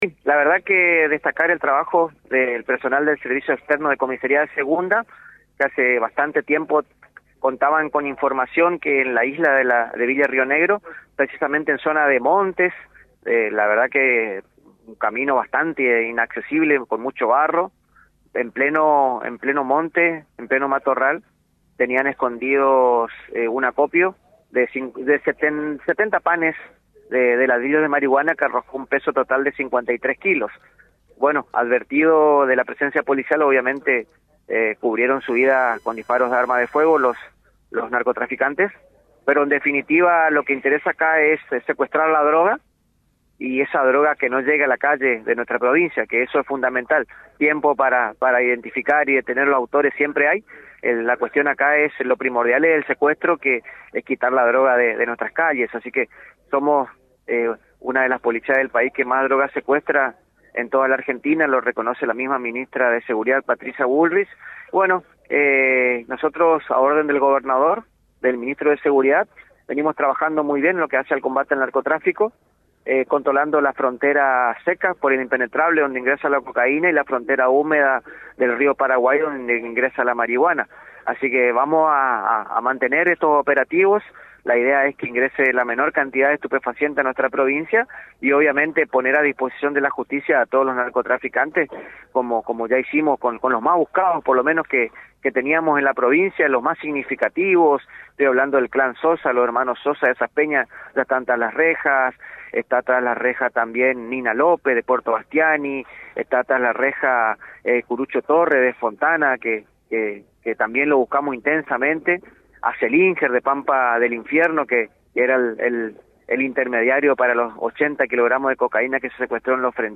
En diálogo con Radio Provincia, Romero repasó un reciente operativo en Villa Río Negro, donde se incautaron 53 kilos de marihuana en una zona montuosa e inaccesible.
Romero cerró la entrevista con un mensaje claro a la comunidad chaqueña: “No nos molesta que nos avisen dónde están los búnkeres, al contrario. Cuanto más colabore la ciudadanía, más rápido actuamos. Nuestro trabajo es detener, allanar, secuestrar droga, y lo vamos a seguir haciendo con el mismo compromiso de siempre”.